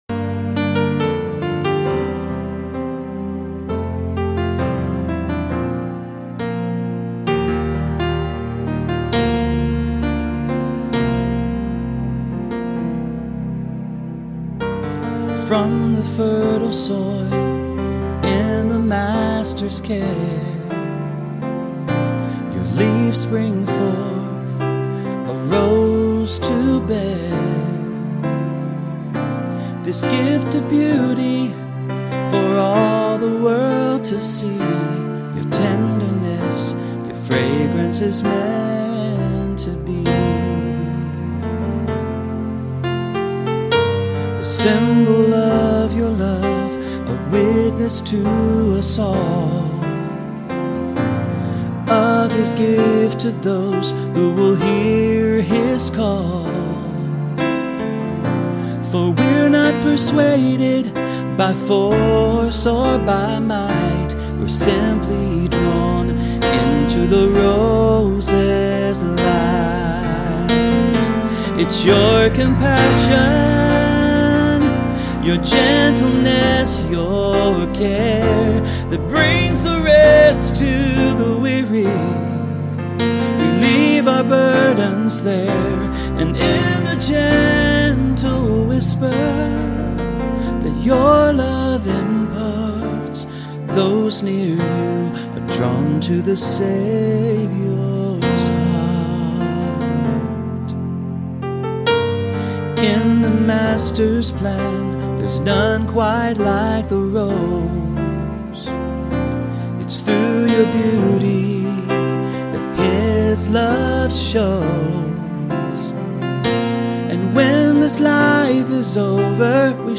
The-Rose-Studio-Version.mp3